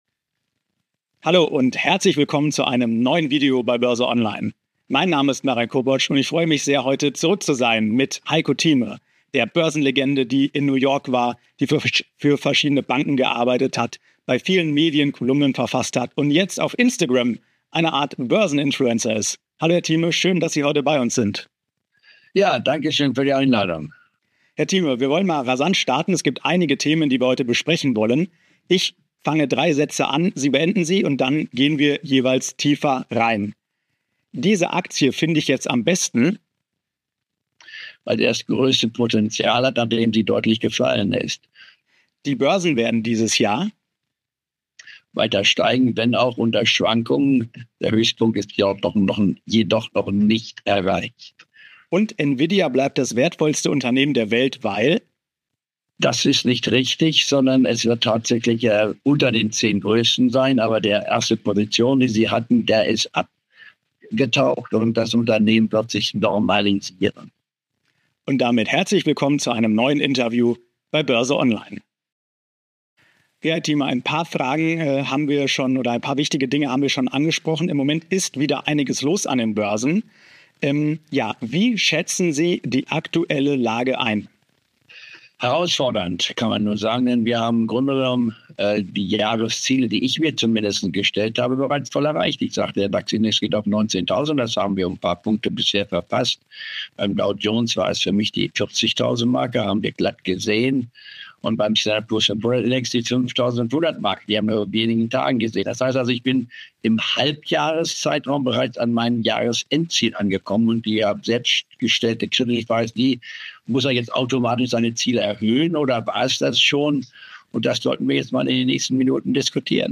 Alles das und noch viel mehr verrät Börsen-Legende Heiko Thieme im Interview.